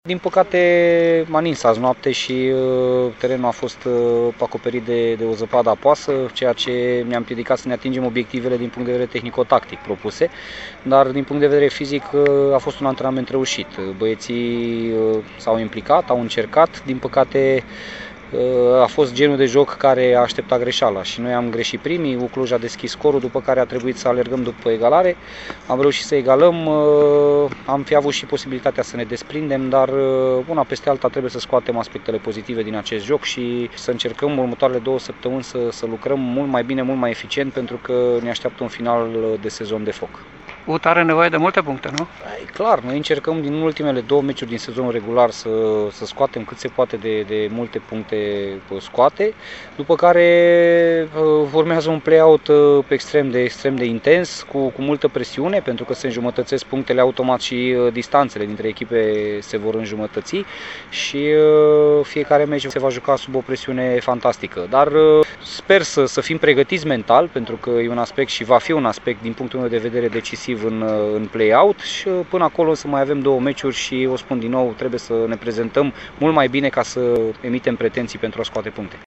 La finalul partidei disputate la Simeria,